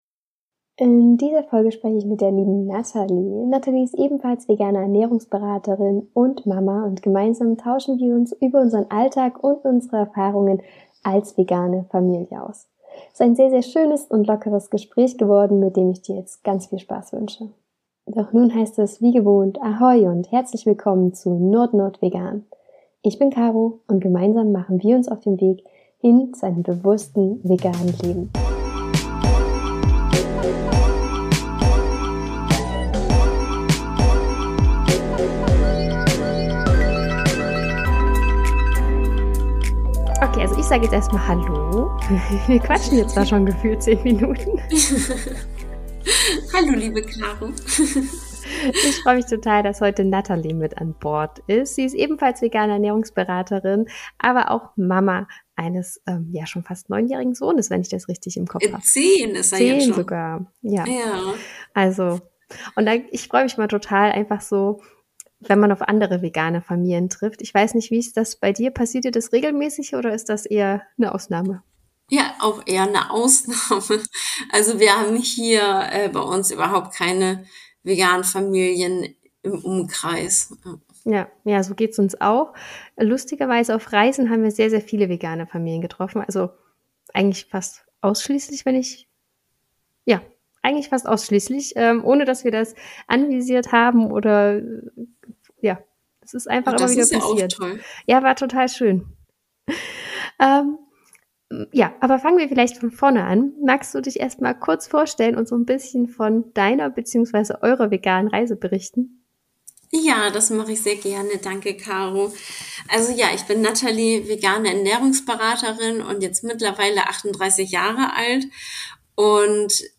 Mama-Talk: So sieht der Alltag veganer Familien aus - Interview